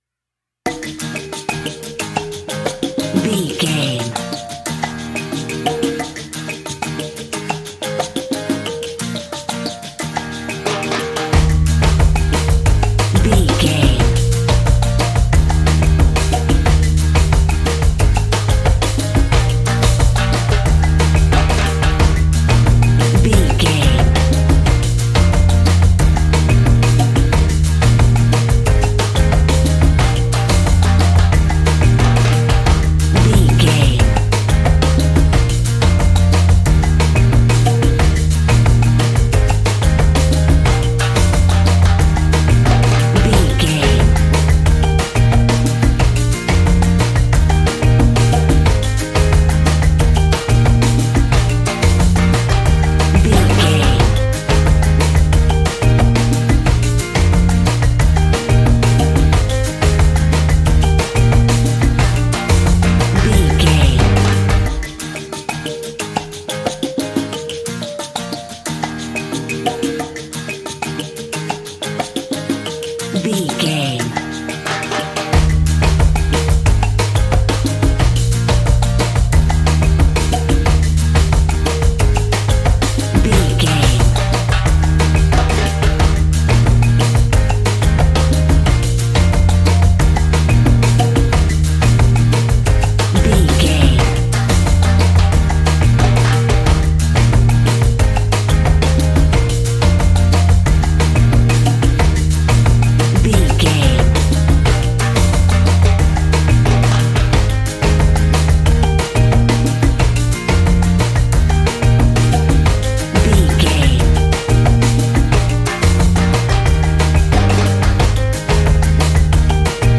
Uplifting
Ionian/Major
E♭
steelpan
drums
bass
brass
guitar